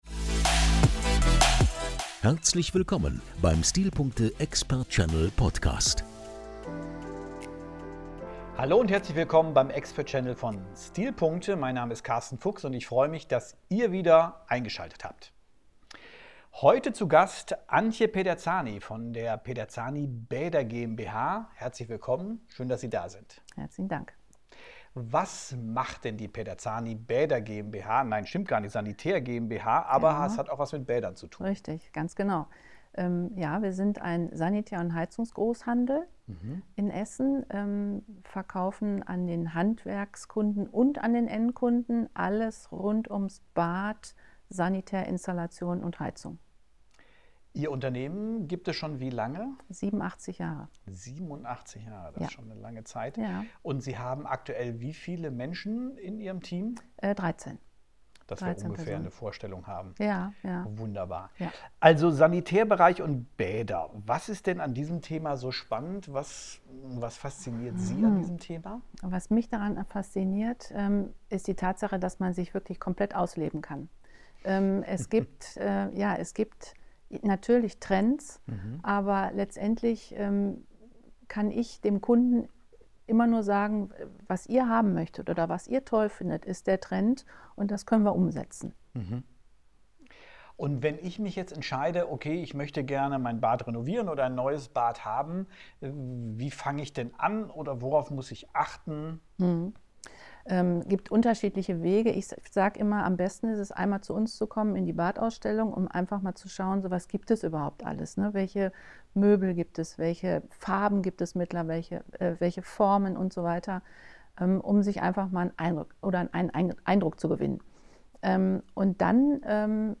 Der STILPUNKTE EXPERT CHANNEL bietet Ihnen faszinierende Einblicke und ausführliche Interviews mit führenden Experten und innovativen Unternehmern aus verschiedenen Branchen.